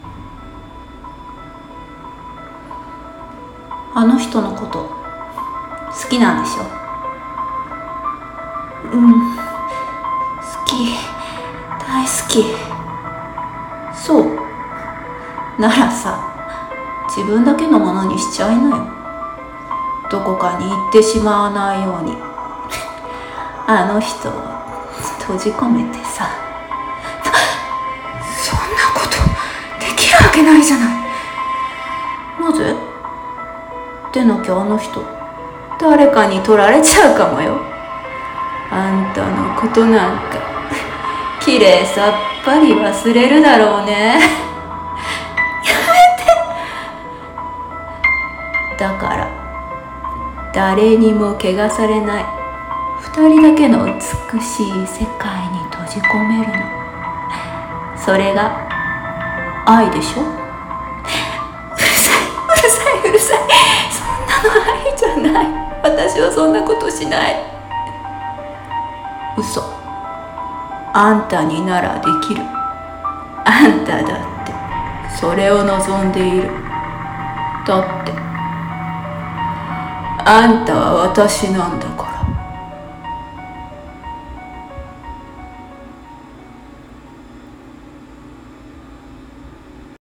【1人声劇】本当の私は。